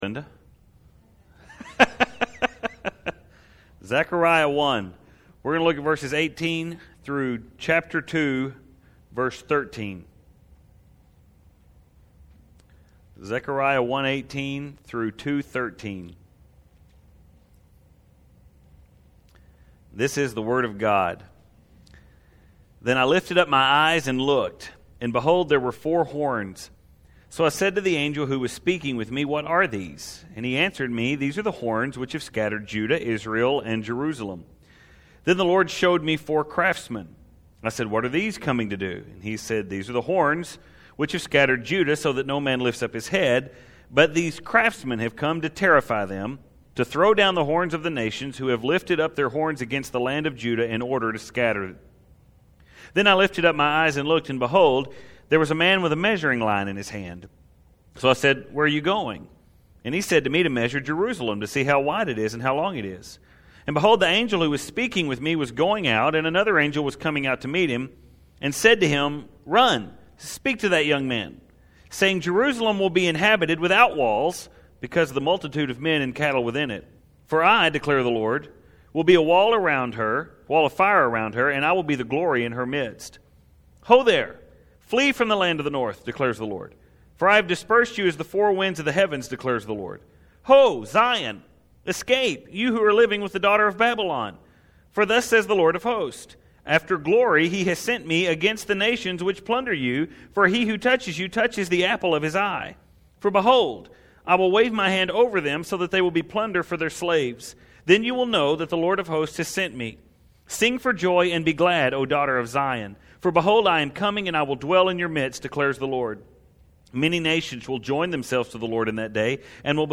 We’ve saw it in both sermons last week and again in both sermons this week.